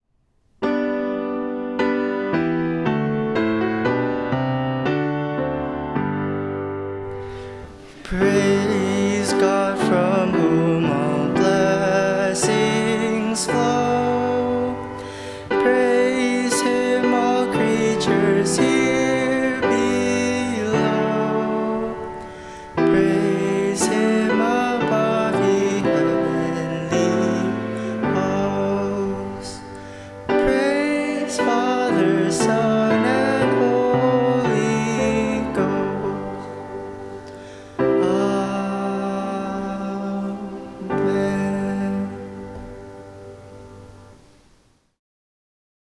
Service of Worship
Doxology